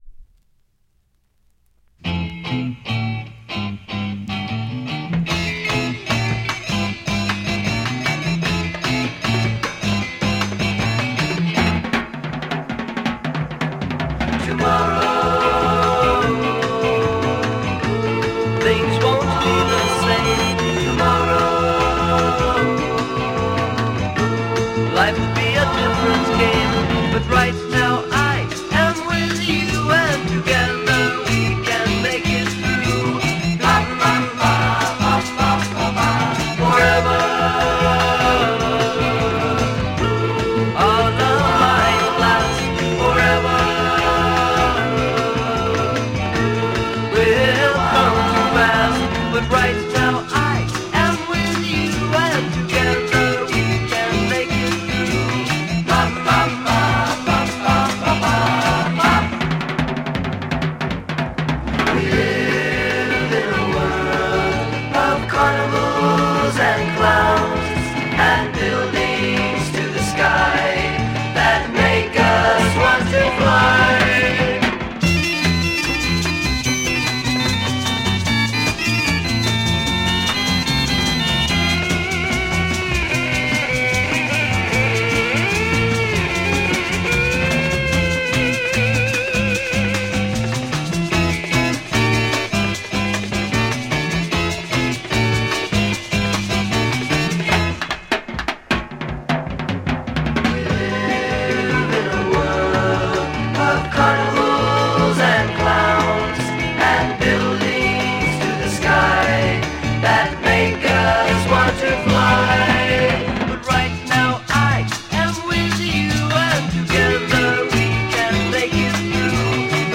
US West coast psych
Classic US garage psych west cost single.